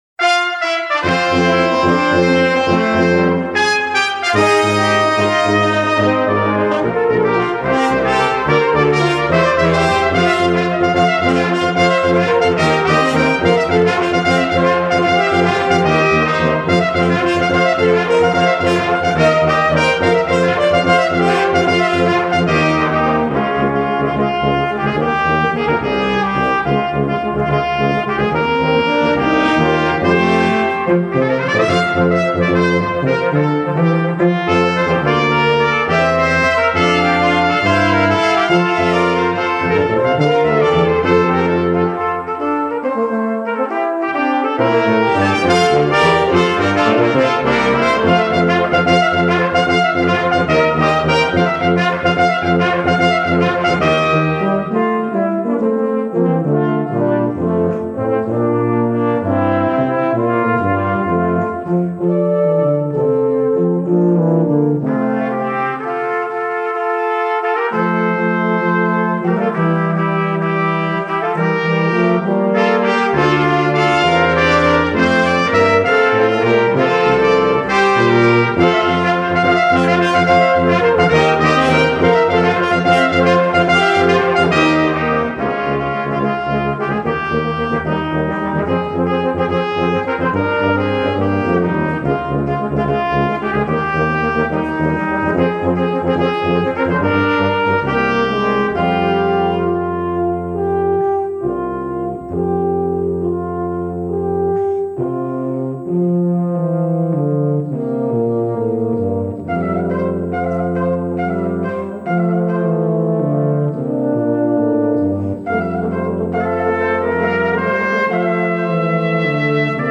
Brass Quintet
recorded by the faculty brass quintet at IUP